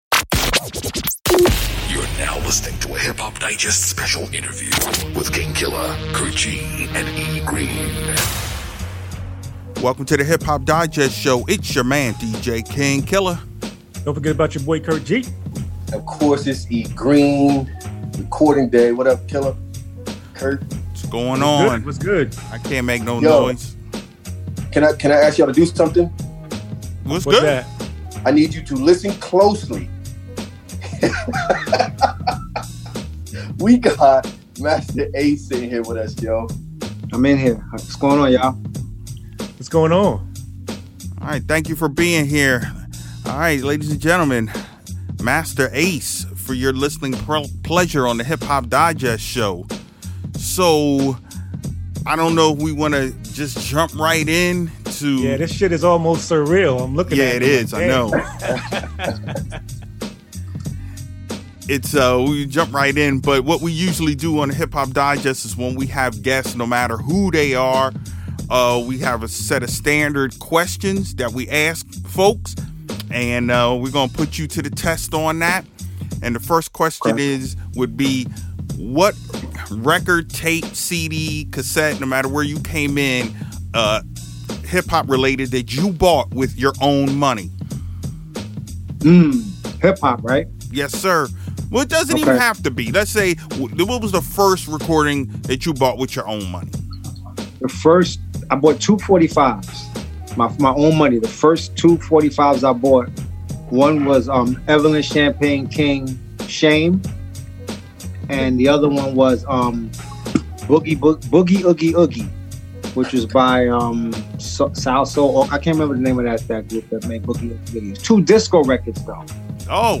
Hip-Hop Digest Show - Masta Ace Interview
Background Music